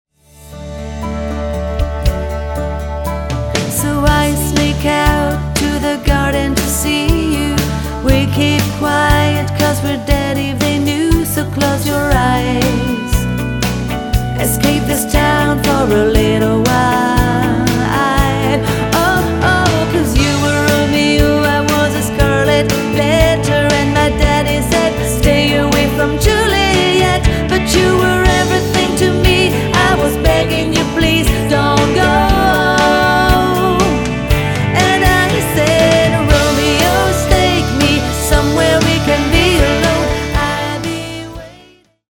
Tour de chant 100% country.